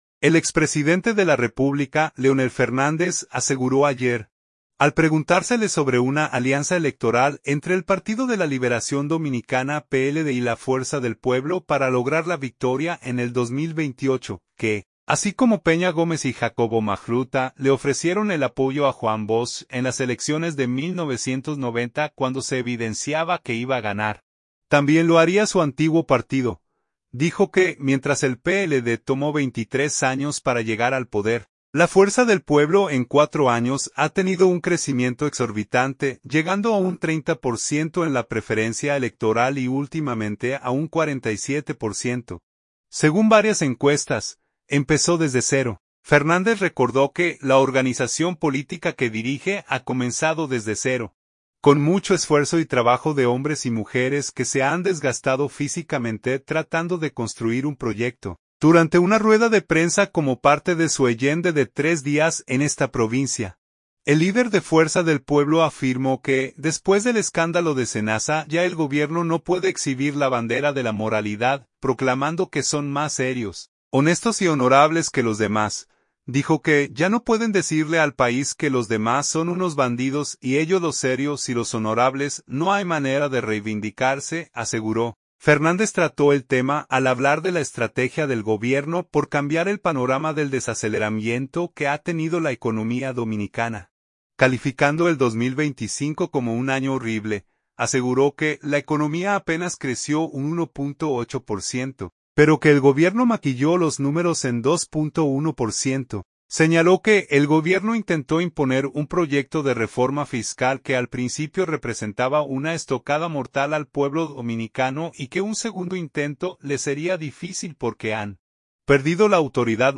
Durante una rueda de prensa como parte de su agenda de tres días en esta provincia, el líder de Fuerza del Pueblo afirmó que después del escándalo de Senasa ya el gobierno no puede exhibir la bandera de la moralidad, proclamando que son más serios, honestos y honorables que los demás.